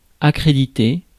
Ääntäminen
IPA: [a.kʁe.di.te]